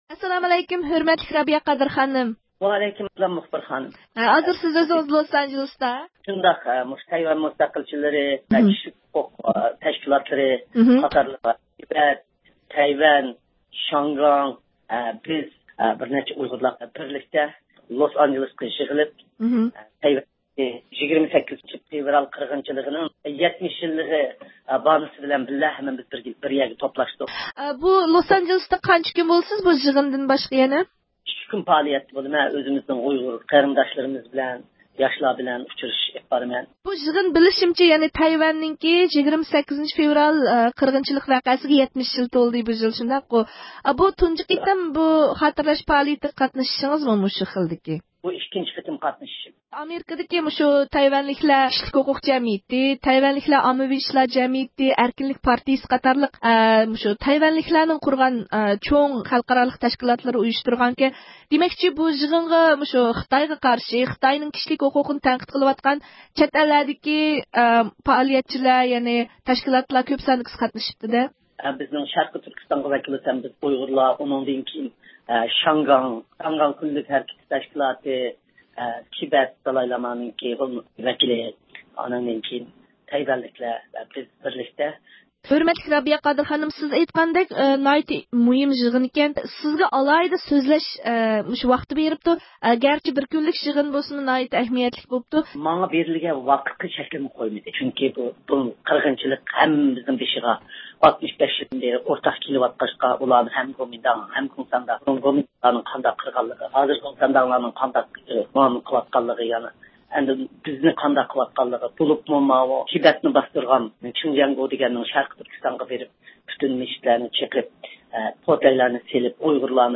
تېلېفون سۆھبىتىنى